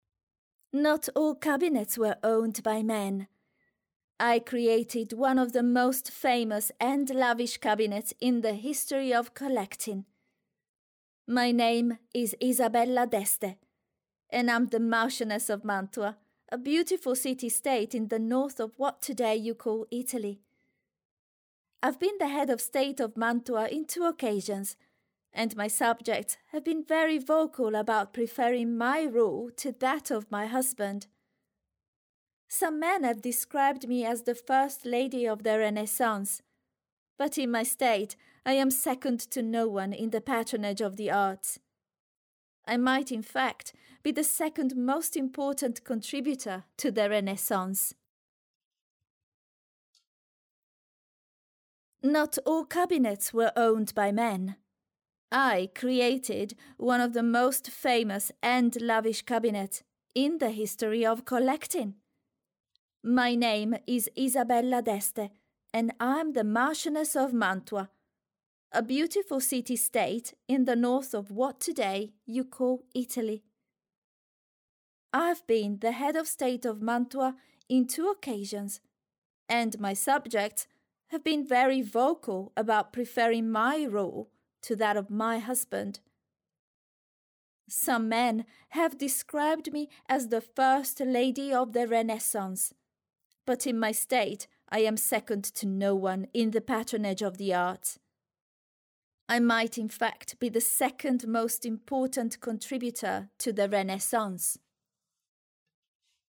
Audio Guides
I am a native Italian voice over artist.
My voice is young, fresh and energetic.
I can record in Italian with a neutral accent or English with great diction and a great soft Italian accent.
Microphone: Rode NT1A
Mezzo-SopranoSoprano